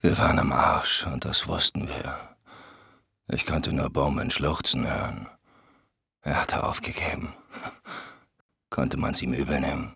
For the game's two protagonists, Reznov and Mason, Effective Media employed well-known dubbing actors Udo Schenk and Tobias Kluckert, who engage in veritable battles of words during the course of the game.